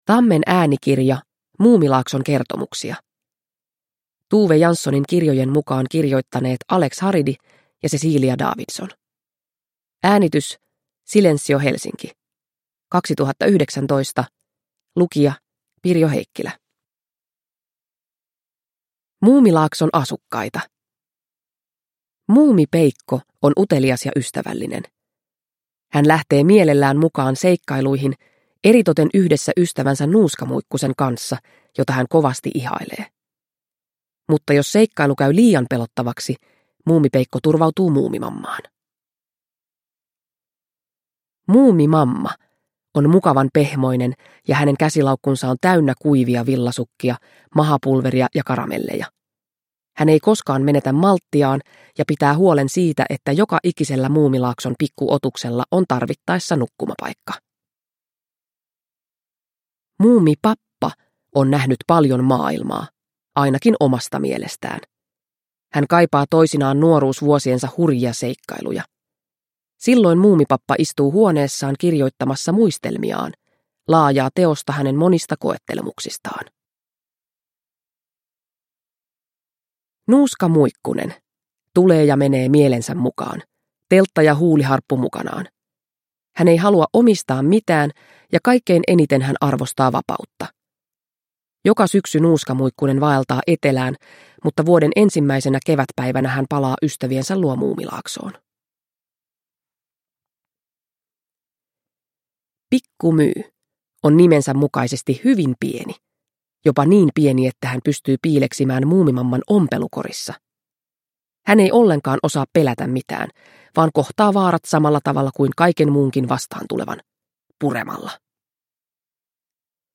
Muumilaakson kertomuksia – Ljudbok